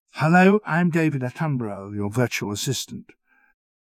hello-virtual-assistant.wav